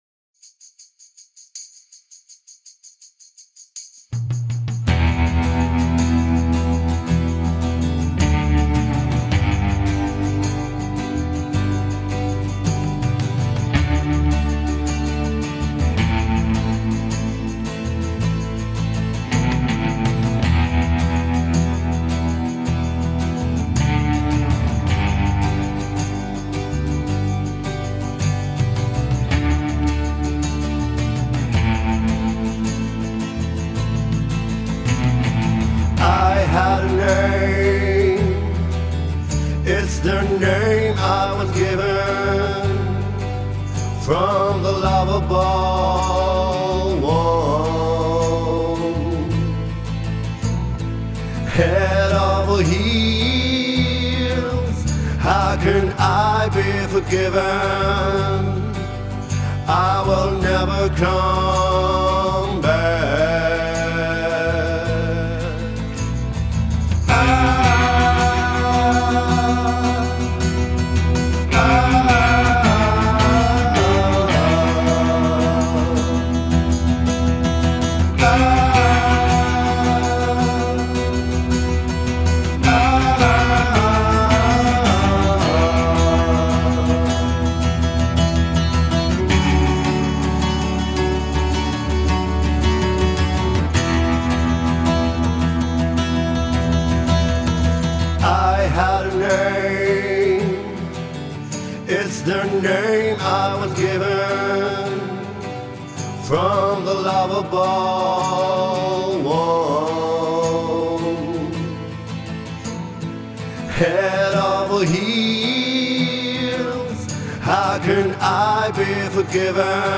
Απαραίτητη μουσική υπόκρουση, το τραγούδι Head Over Heels που γράφτηκε ειδικά για την παράσταση και έτσι, όσα Shazam και αν κάνετε, δεν θα το βρείτε.